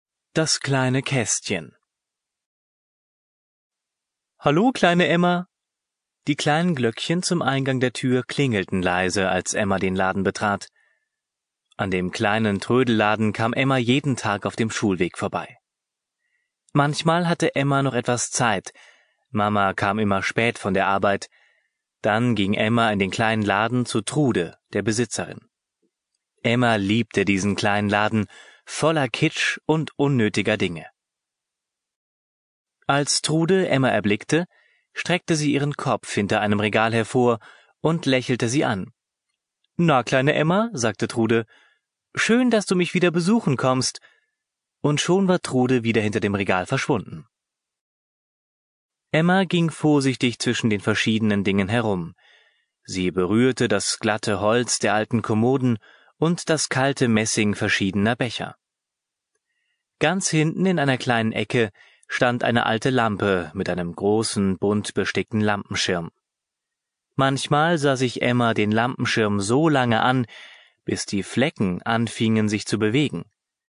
Alle Geschichten sind von deutschen Muttersprachlern gesprochen, um den Kindern ein Gefühl für die Sprachmelodie und Aussprache zu vermitteln.
Die Audio-CD Deutsche Kindergeschichten, Josef das Gespenst enthält 10 deutsche Kindergeschichten und richtet sich an alle Eltern, die ihre Kinder frühzeitig mit gutem und richtigem Deutsch vertraut machen möchten und die ihren Kindern die Möglichkeit geben möchten, ihre sprachlichen Fähigkeiten so früh wie möglich zu entwickeln. Alle Geschichten sind von ausgebildeten Sprechern gesprochen, um den Kindern ein Gefühl für die Sprachmelodie und Aussprache des Hochdeutschen zu vermitteln.